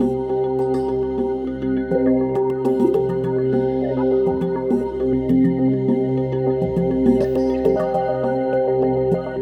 LightPercussiveAtmo4_102_C.wav